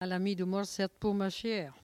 Patois - archive
Catégorie Locution